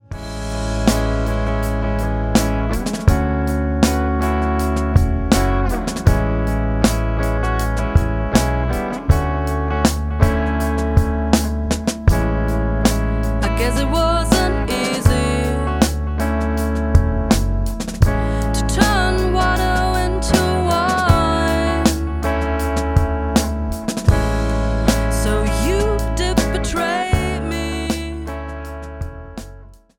Bandrecording EP